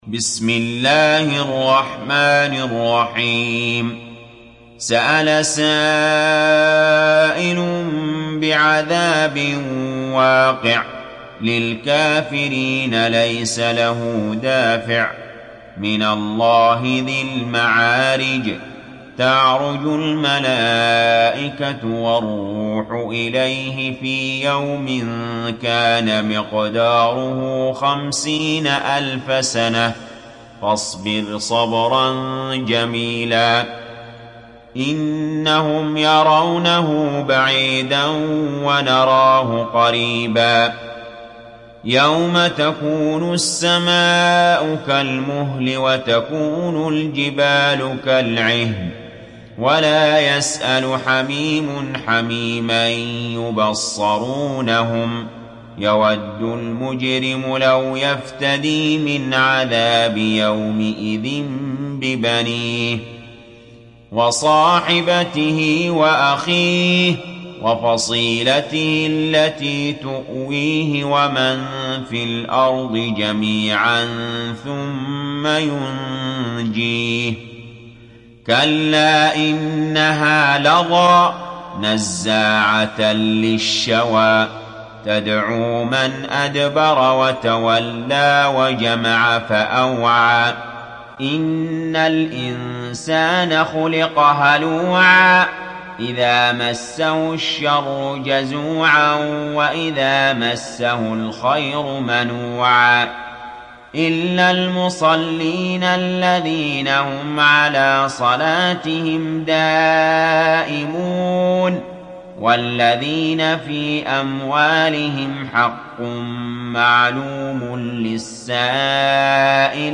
تحميل سورة المعارج mp3 علي جابر (رواية حفص)
تحميل سورة المعارج mp3 بصوت علي جابر برواية حفص عن عاصم, تحميل استماع القرآن الكريم على الجوال mp3 كاملا بروابط مباشرة وسريعة